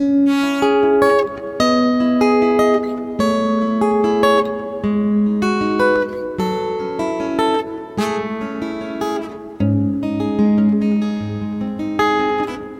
时间 古典吉他75BPM
Tag: 75 bpm Acoustic Loops Guitar Acoustic Loops 2.16 MB wav Key : Unknown